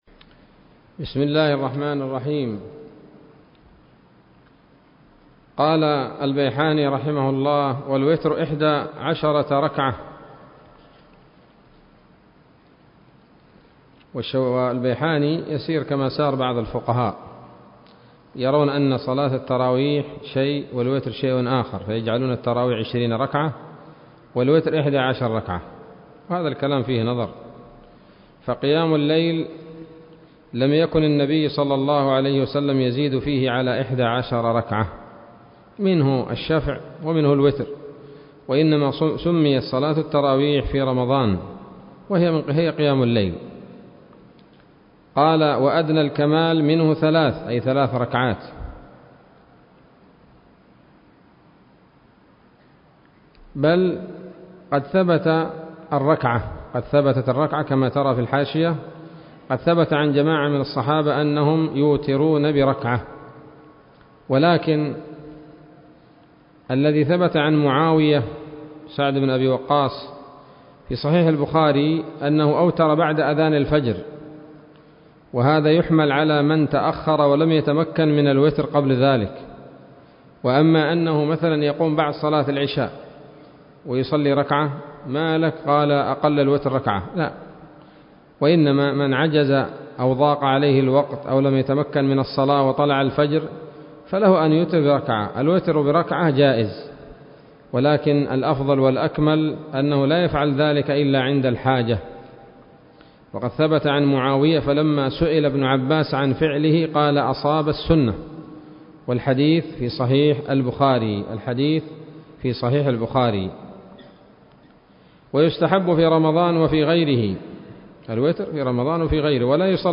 الدرس السابع من تحفة رمضان للعلامة البيحاني [1443هـ]